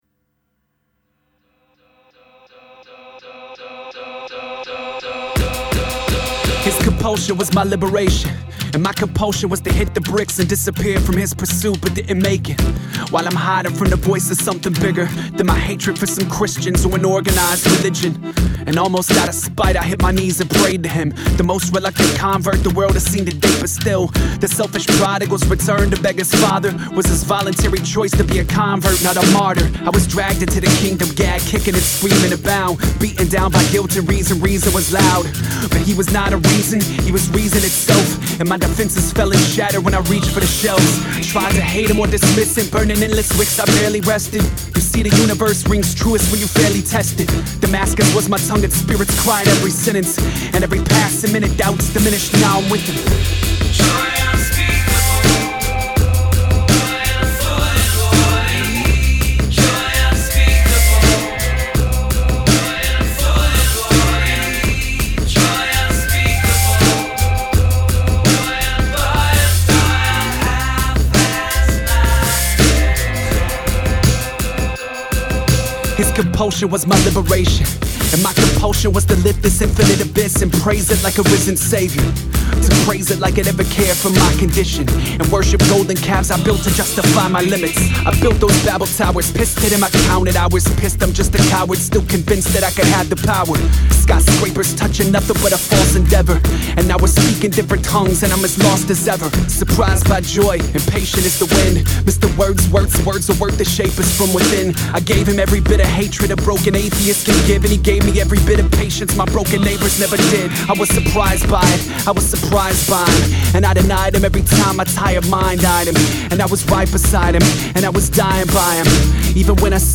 O estilo musical escolhido foi o hip-hop.